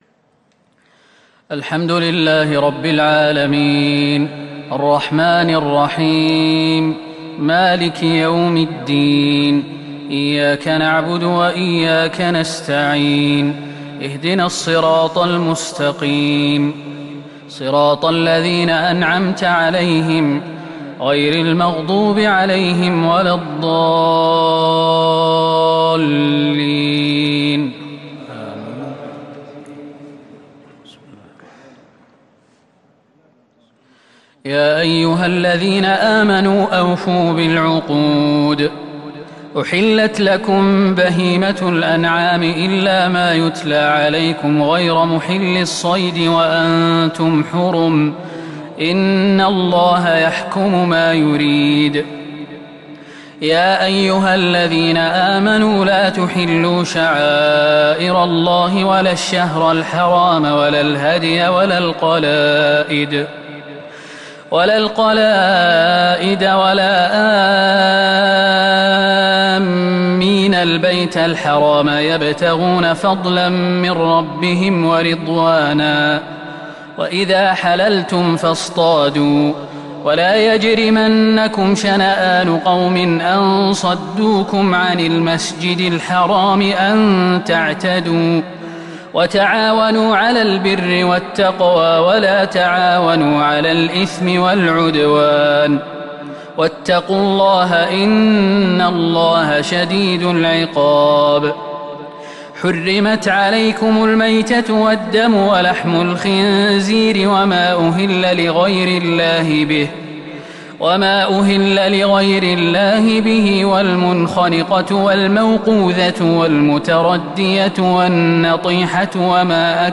تراويح ليلة 8 رمضان 1442هـ من سورة المائدة {1-40} Taraweeh 8th night Ramadan 1442H Surah Al-Maidah > تراويح الحرم النبوي عام 1442 🕌 > التراويح - تلاوات الحرمين